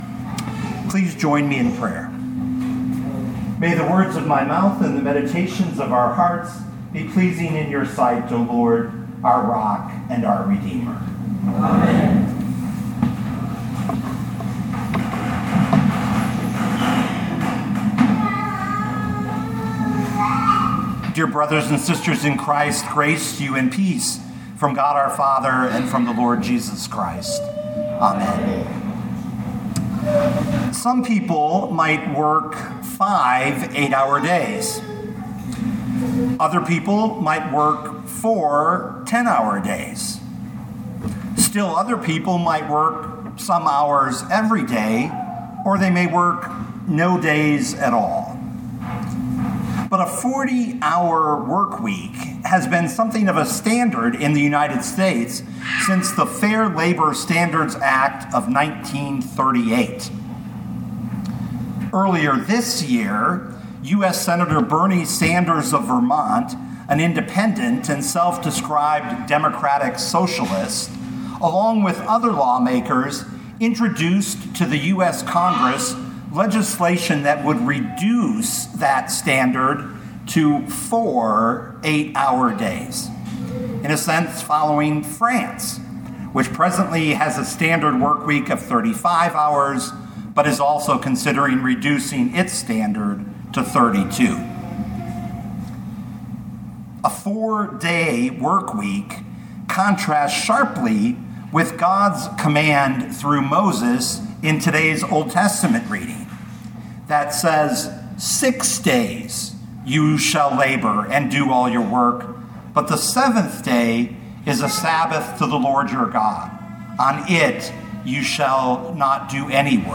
2024 Mark 2:23-3:6 Listen to the sermon with the player below, or, download the audio.